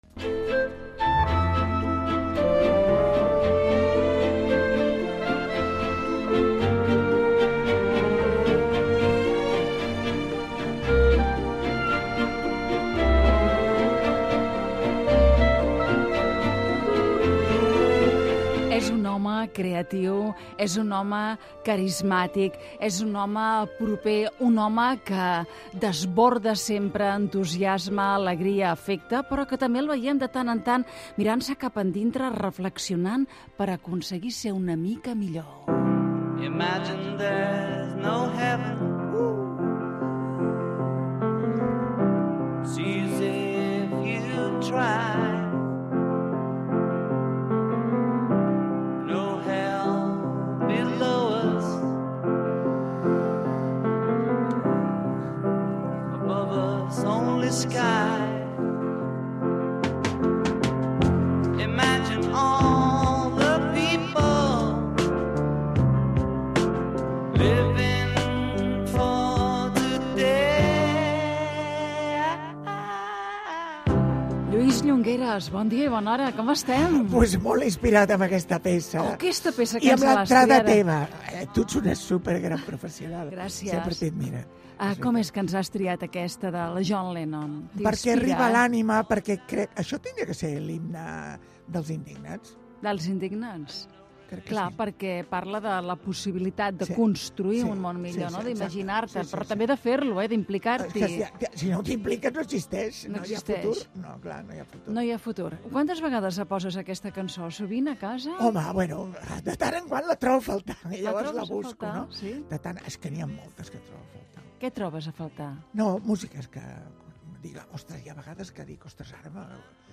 Secció "El divan" amb una entrevista al perruquer Lluís Llongueras
Entreteniment